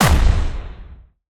poly_explosion_nuke5.wav